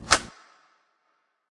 Gun FX " Bersa皮套2
Tag: 皮套 手枪 FX